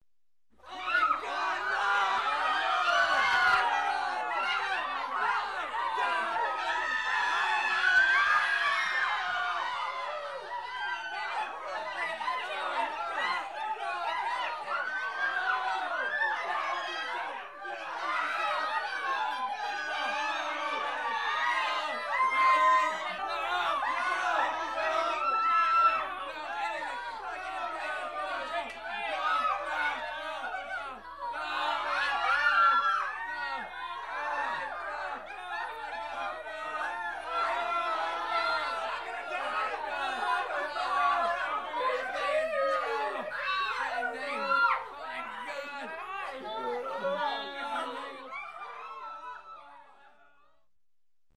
Звуки человеческого испуга
Звук испуганной толпы (волнение)
zvuk_ispugannoj_tolpi_volnenie_vub.mp3